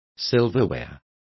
Complete with pronunciation of the translation of silverware.